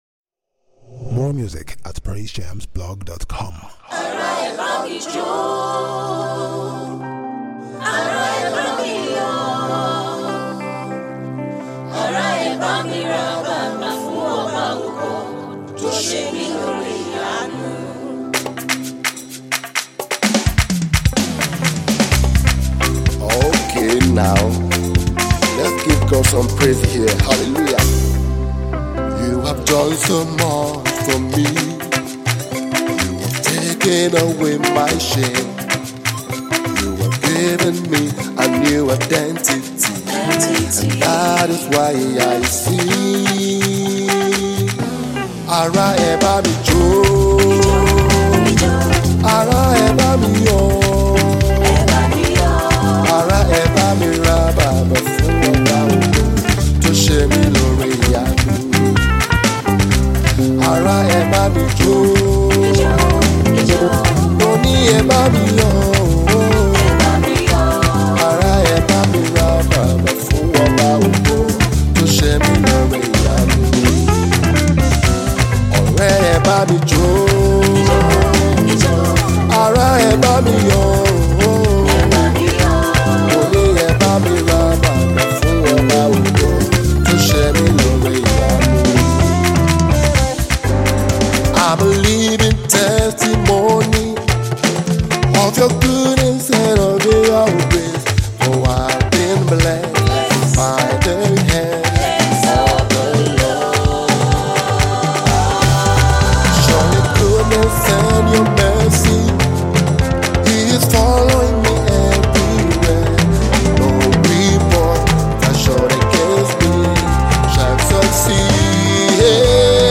Nigerian gospel music minister and prolific songwriter
urban riddim tune to make you dance